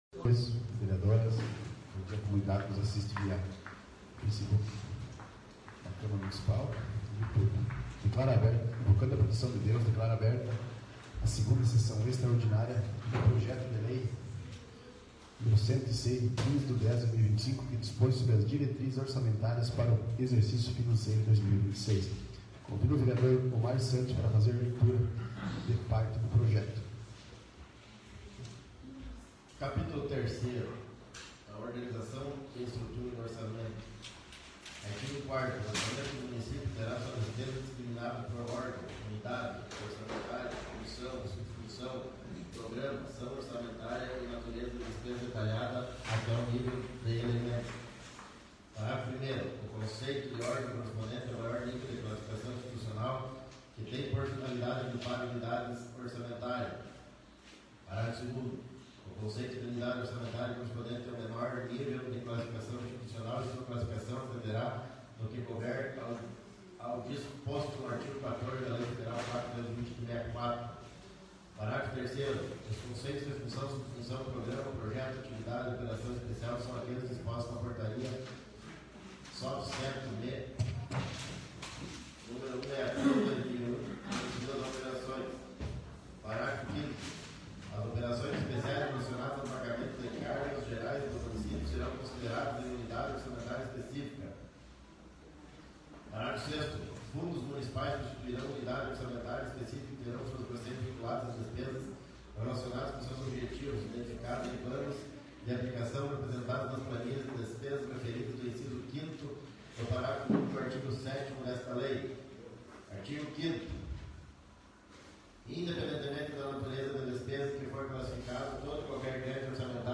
8ª Extraordinária da 1ª Sessão Legislativa da 16ª Legislatura